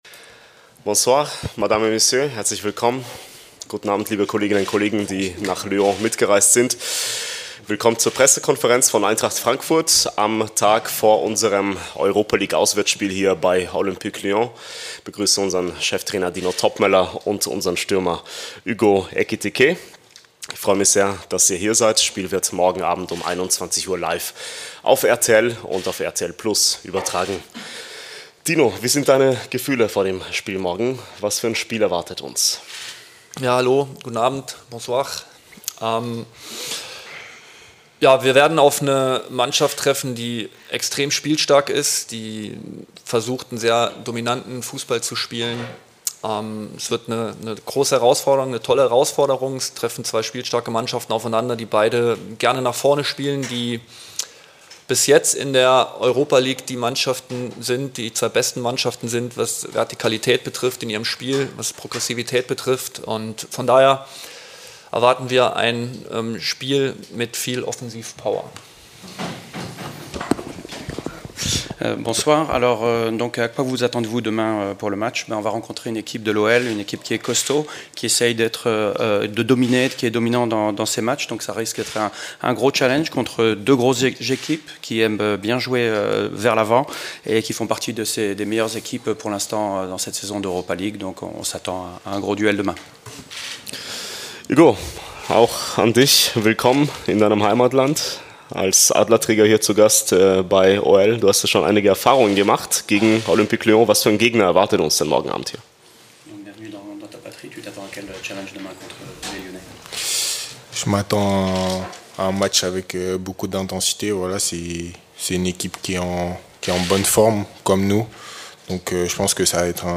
Die Pressekonferenz mit unserem Cheftrainer Dino Toppmöller und Angreifer Hugo Ekitiké aus Frankreich vor dem Europa-League-Auswärtsspiel.